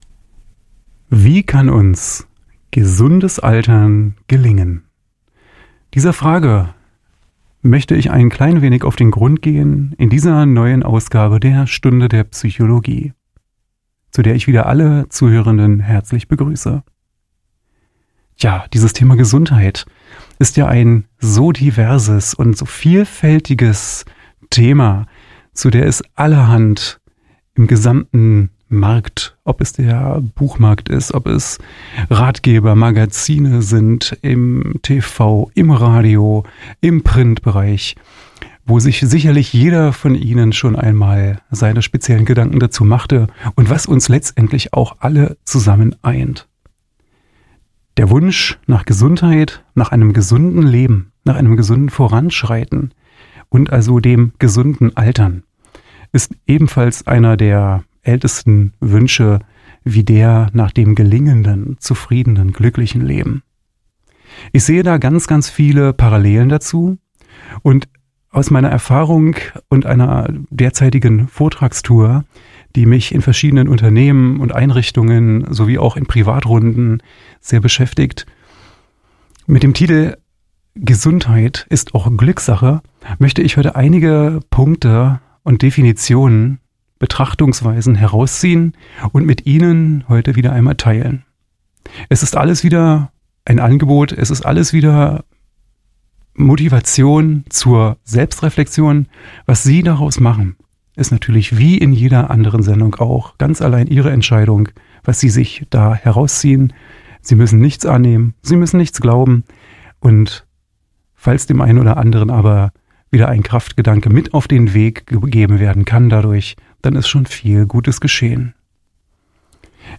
Darum geht es in Vorträgen und Gesprächen